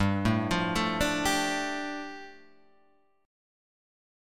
G7sus2 chord {3 0 3 2 3 3} chord